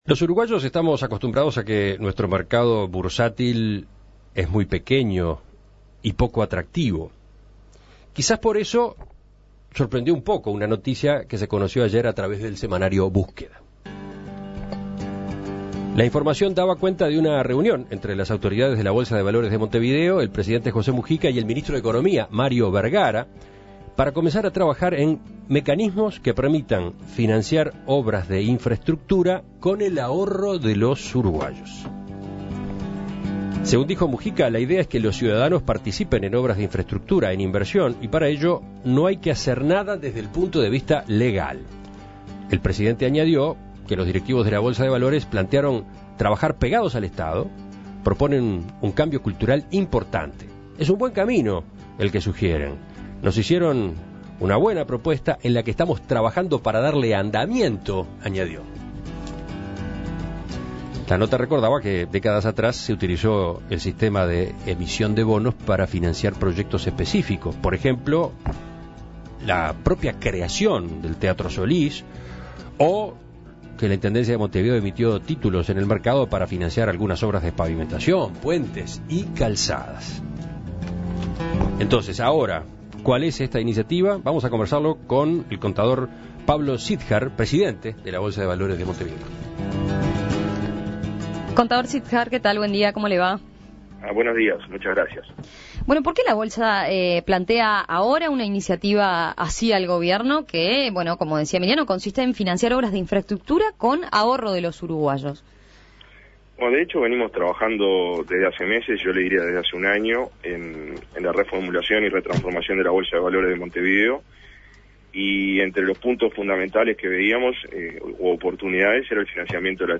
(emitido a las 8.37 hs.)